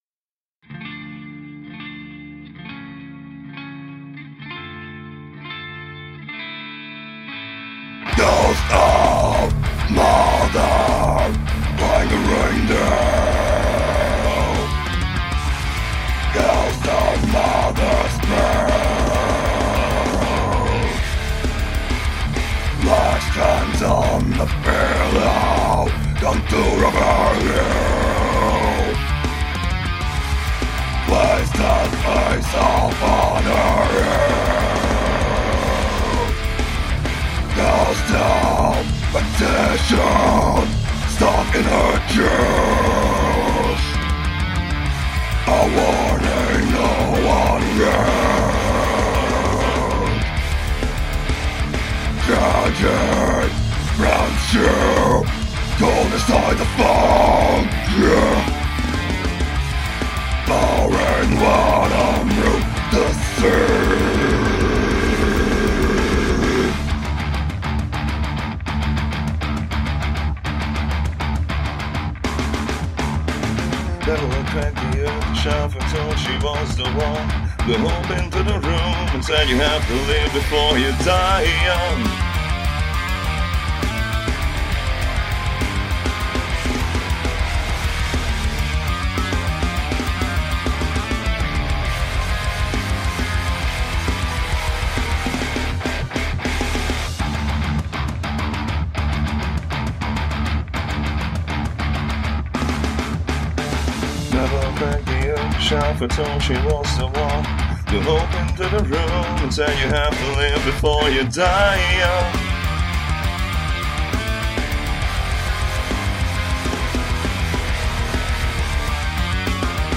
ترکیبی از دث متال، پراگرسیو راک و ملودیک دارک متال
پراگرسیو دث‌متال